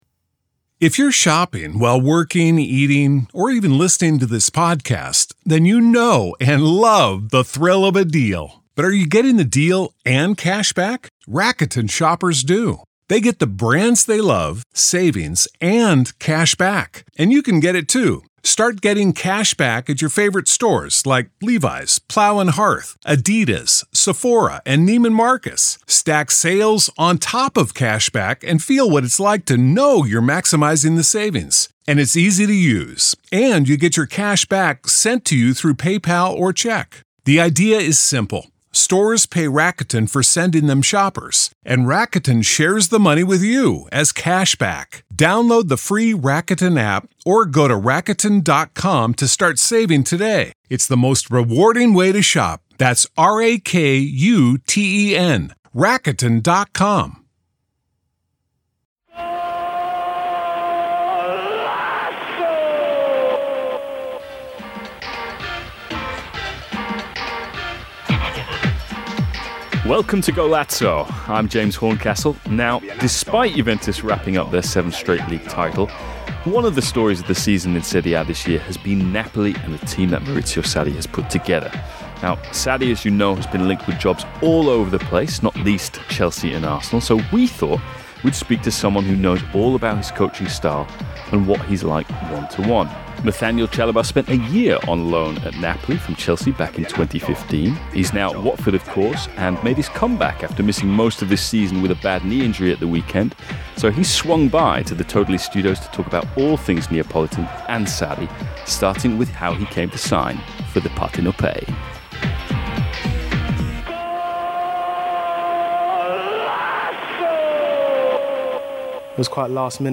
Facebook Twitter Headliner Embed Embed Code See more options James Horncastle speaks to Watford's Nathaniel Chalobah about his 2015 loan spell at Napoli from Chelsea. What was it like working with Maurizio Sarri? How did the Partenopei fans react to this young and - at the time- relatively unknown English midfielder?